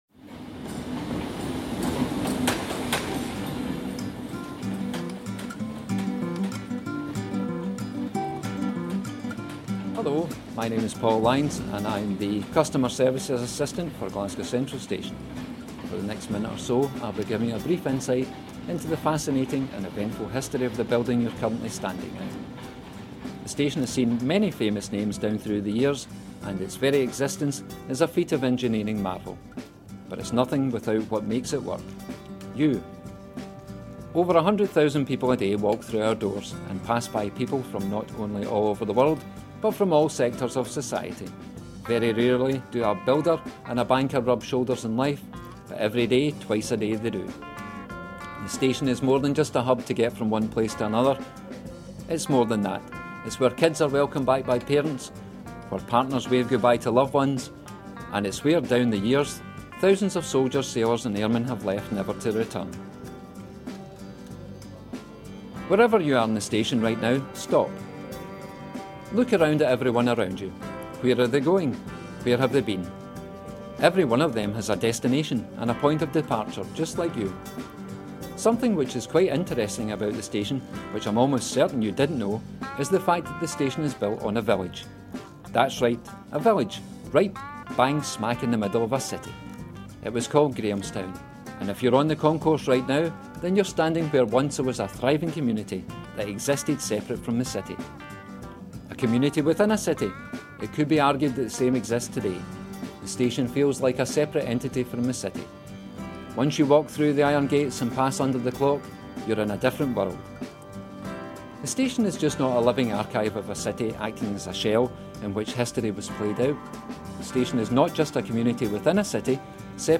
Music Bed: ‘See You Later’, by Pitx Creative Commons License